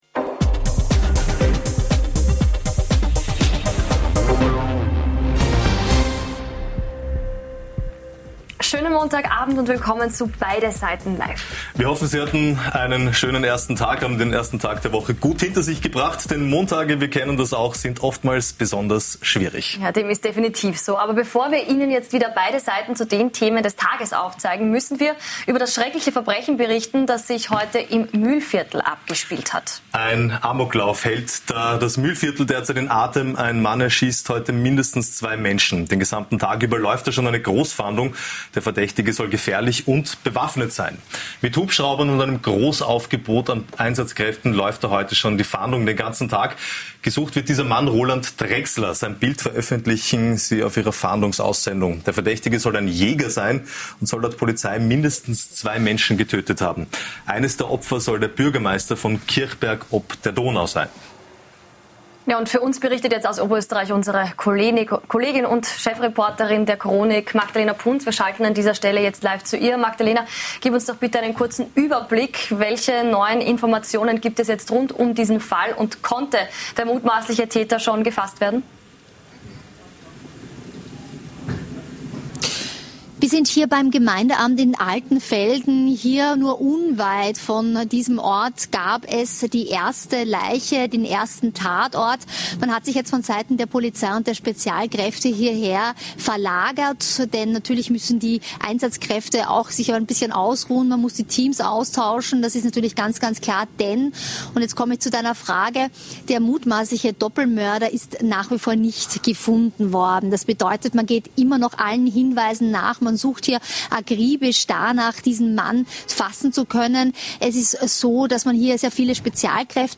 Beschreibung vor 1 Jahr Wir beleuchten beide Seiten zu folgenden Themen: Neo-Nationalratspräsident Rosenkranz will Orban ins Parlament einladen Schwarz-Rote Koalitionssuche: Sicherheit und Neutralität ein Stolperstein? Milchstreit zwischen Bauern und Handelsriesen eskaliert Und nachgefragt haben wir heute bei gleich zwei Gästen - im großen Beide Seiten Live Duell mit der ehemaligen ÖVP-Ministerin Andrea Kdolsky und Sibylle Hamann, ehemalige Grünen-Politikerin.